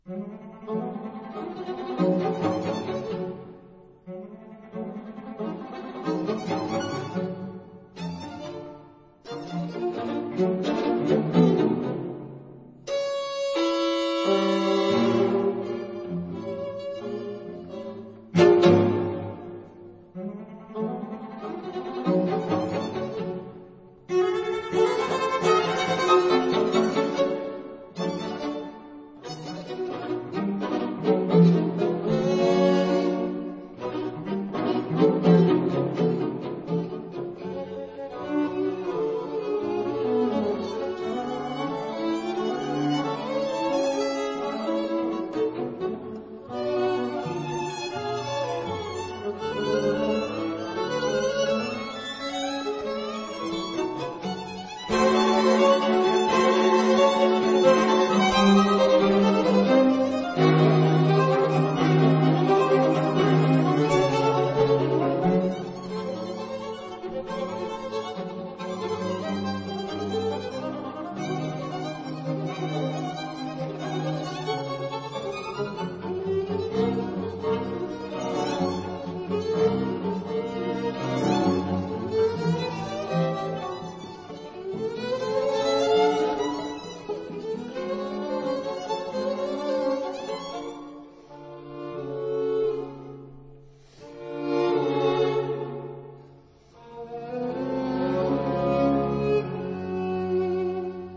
法朗克的弦樂四重奏則是更加收斂情感，
但卻有著歌曲般的優美，與動態對比。
晚安曲（試聽一、二）選了兩段弦樂四重奏，分別是第一、二樂章。